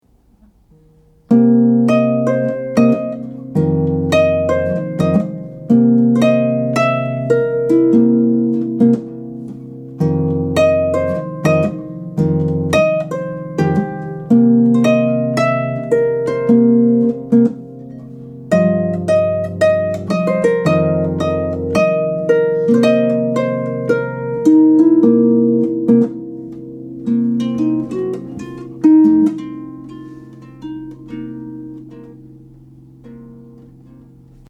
is for solo lever or pedal harp